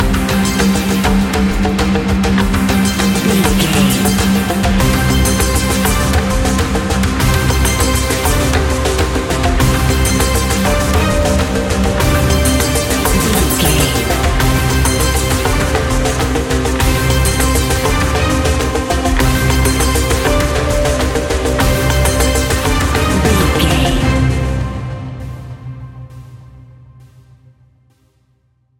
Ionian/Major
electronic
techno
trance
synths
synthwave